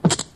• REVOLVER CLICK.wav
REVOLVER_CLICK_Z5p.wav